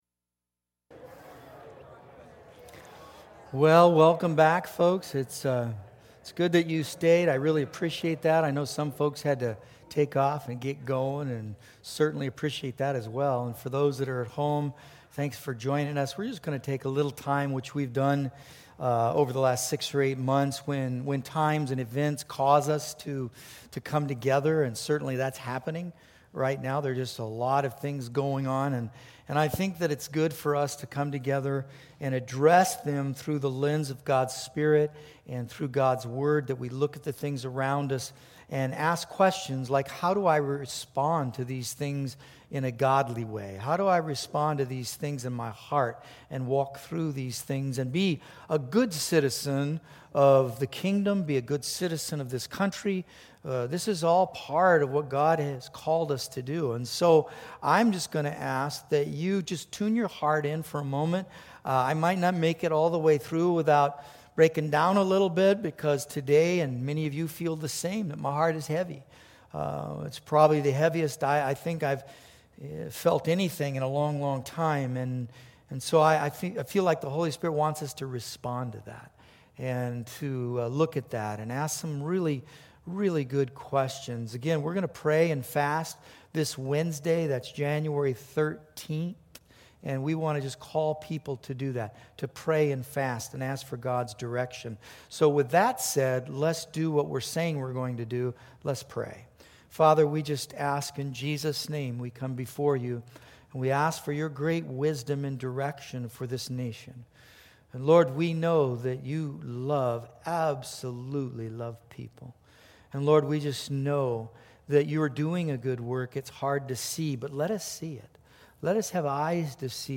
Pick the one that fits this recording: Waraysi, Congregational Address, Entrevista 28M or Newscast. Congregational Address